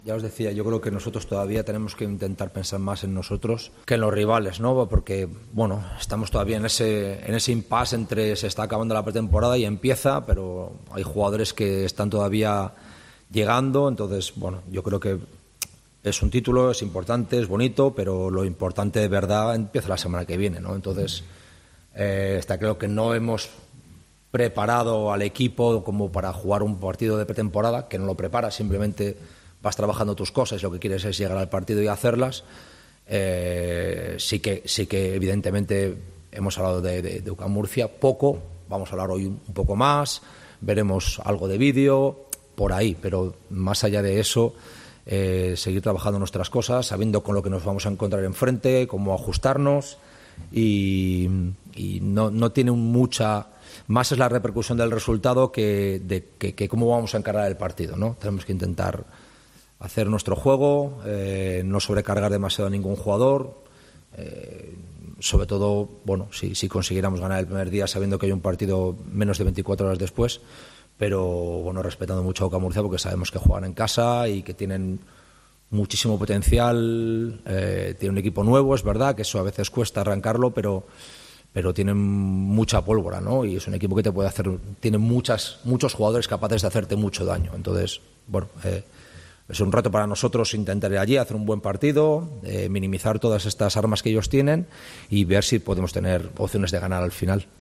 El entrenador del Unicaja Ibon Navarro ha comparecido en la Sala de prensa del Martín Carpena para analizar el estado del equipo antes de desplazarse hoy a Murcia, donde disputará a partir mañana las semifinales de la Supercopa Endesa frente al conjunto anfitrión, el UCAM Murcia (21:30 horas, retransmitido en televisión po Movistar Deportes y en Deportes COPE Málaga 97.1FM - 882AM e internet).